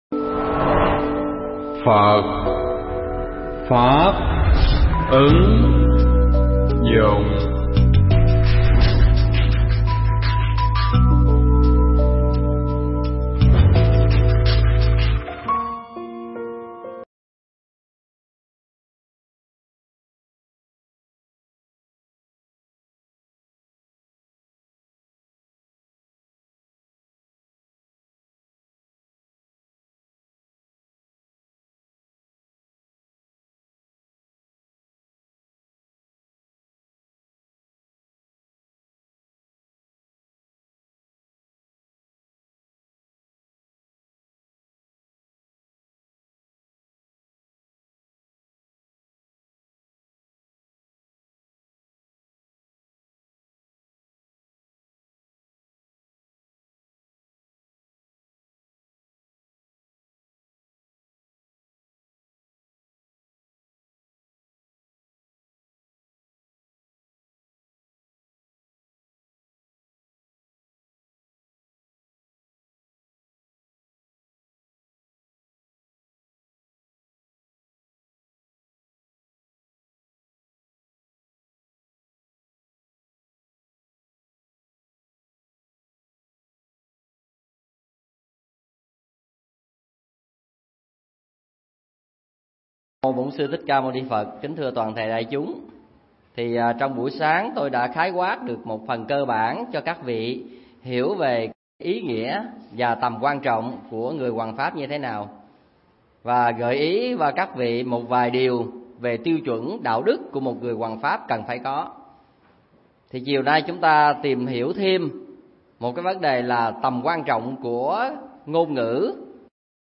Bài pháp thoại Để Trở Thành Giảng Sư Phần 2
tại Thiền Viện Sơn Thắng (xã Thanh Đức, huyện Long Hồ, tỉnh Vĩnh Long)